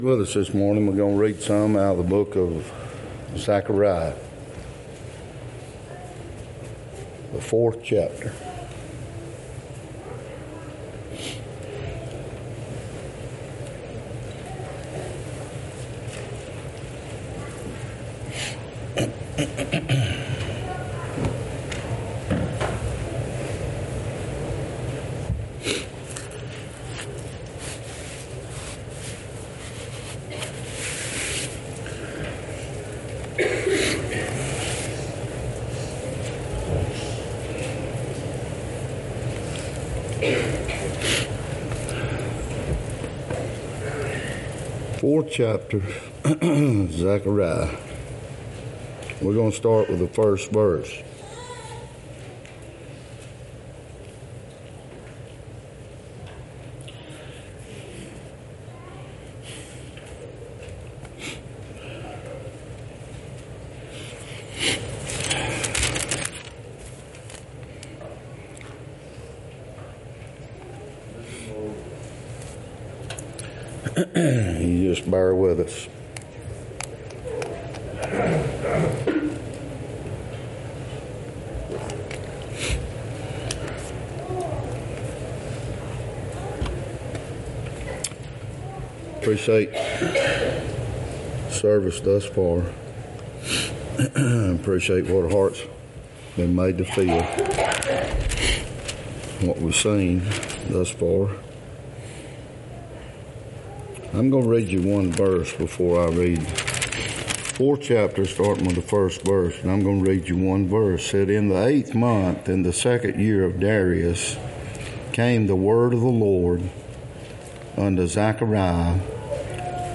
1 Kings 20:35-43 John 17:8-15 James 1:27 Service Type: Sunday 2024 A relationship with God.